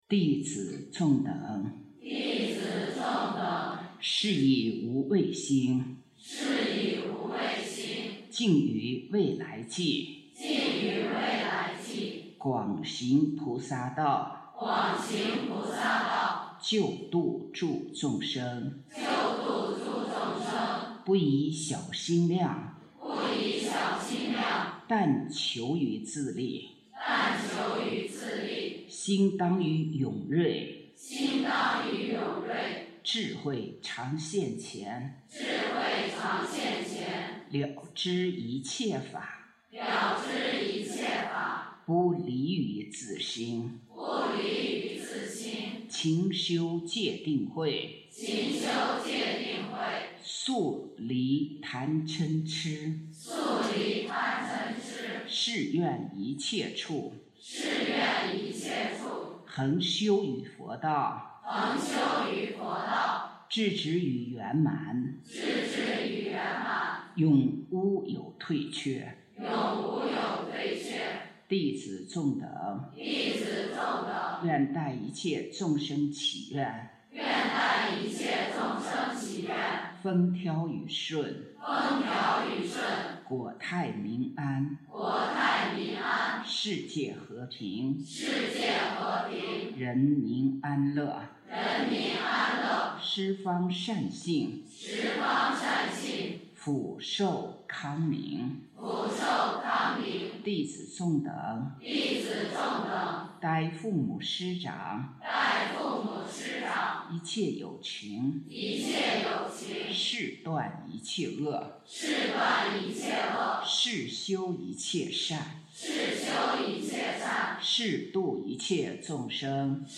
师父领众祈愿.mp3